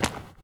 pedology_silt_footstep.3.ogg